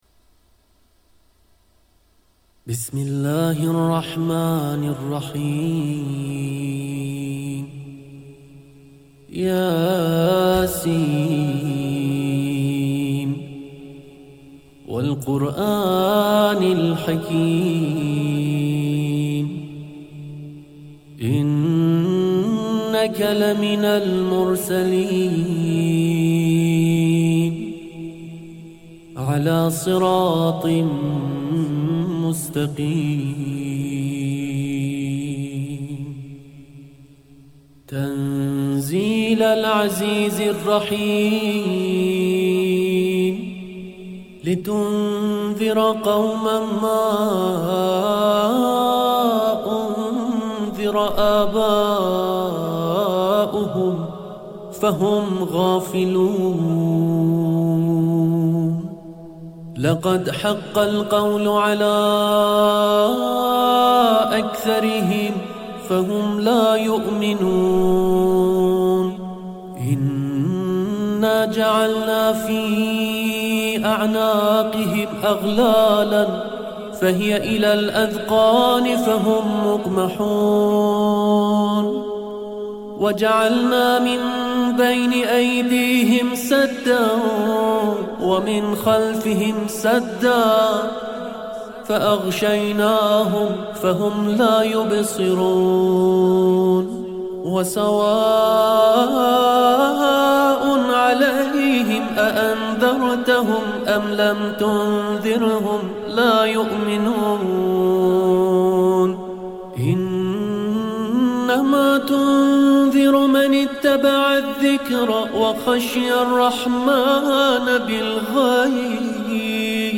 Kur'an Tilaveti